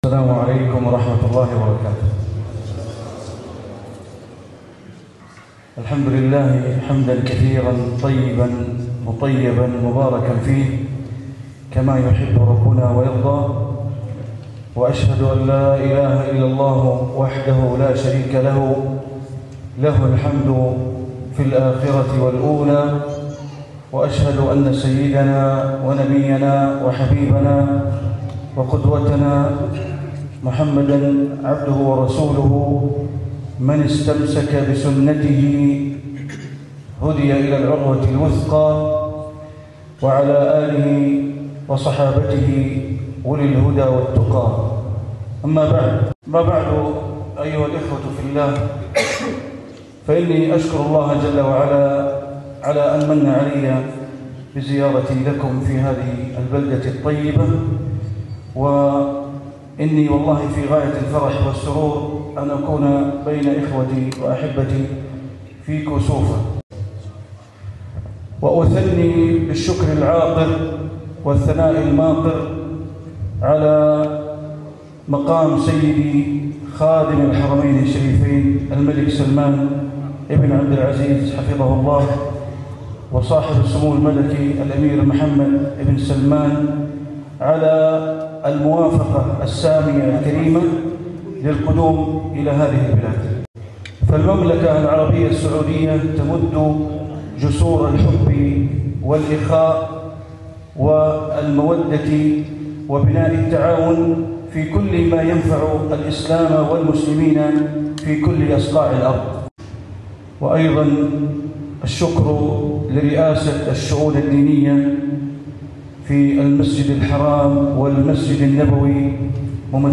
كلمة بعنوان (إنما المؤمنون إخوة) ألقاها الشيخ بندر بليلة في بريشتينا بدولة كوسوفو ١٢ ذو القعدة ١٤٤٦ > زيارة الشيخ بندر بليلة الى جمهورية كوسوفو > المزيد - تلاوات بندر بليلة